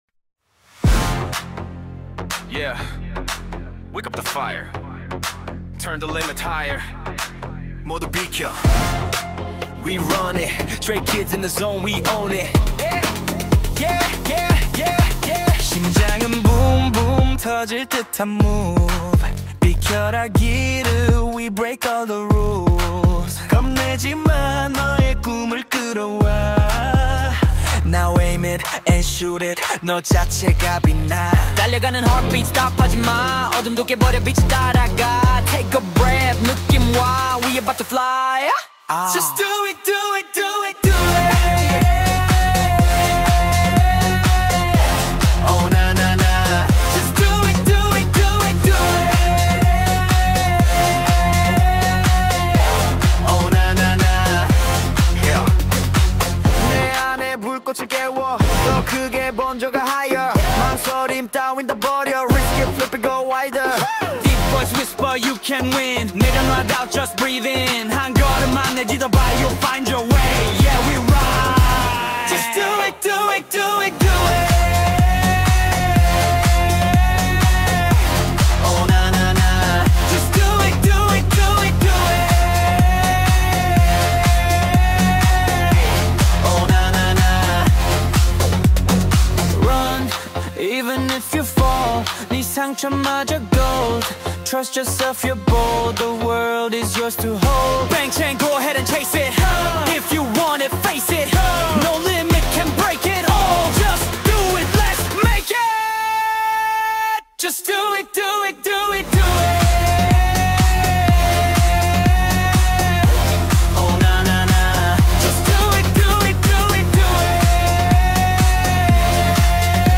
южнокорейского бой-бэнда